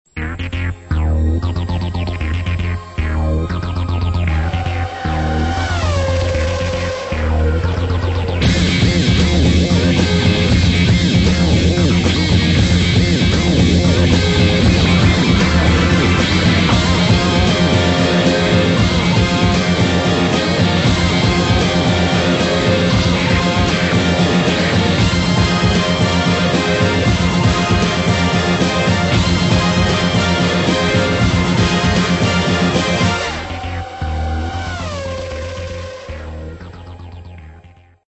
groooovy